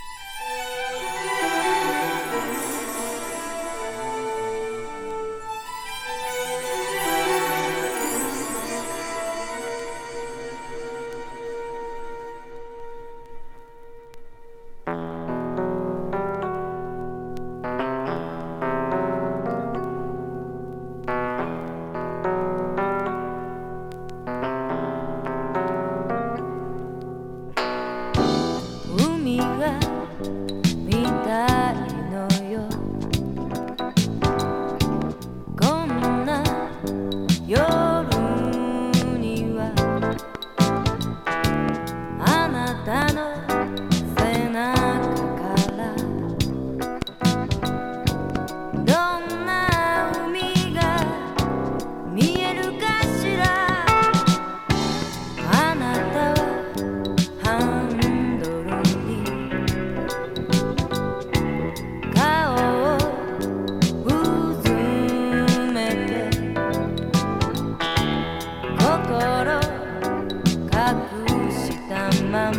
B面にキズ有。ノイズ出る箇所有。
Japanese 和ソウル / ディスコ / ファンク レコード